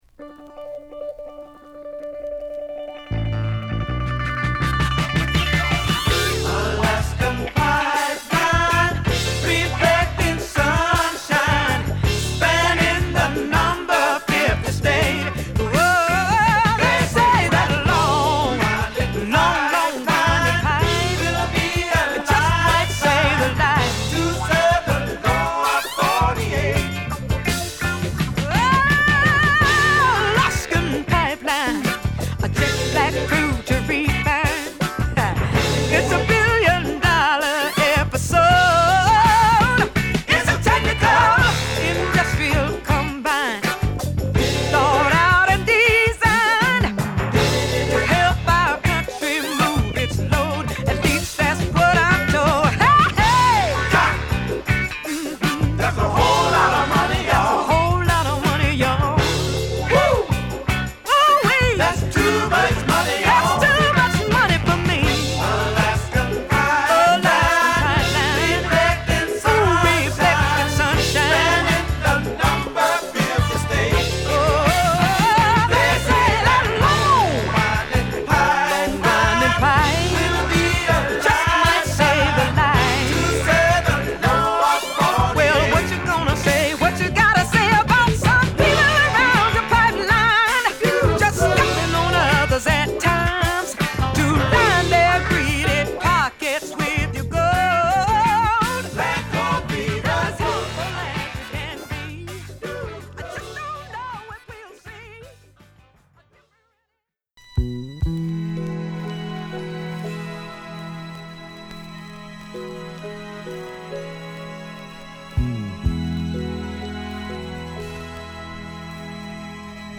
ドラマチックなアレンジが効いた